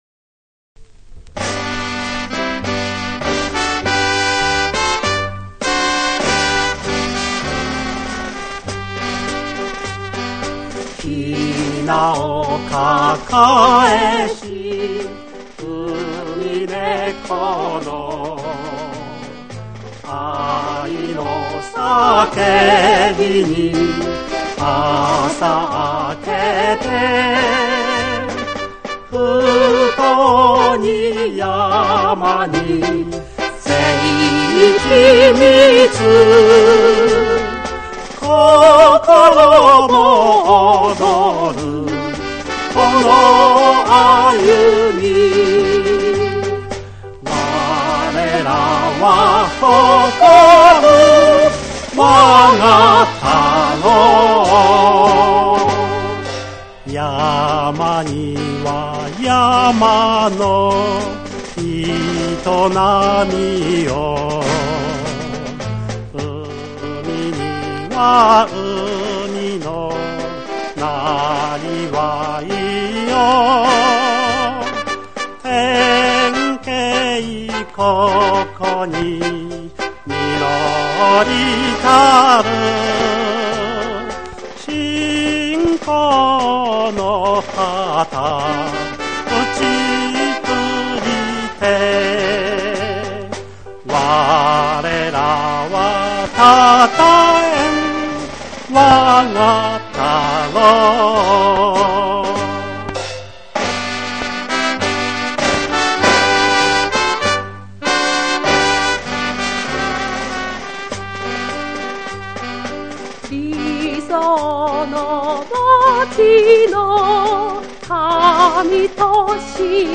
（歌あり）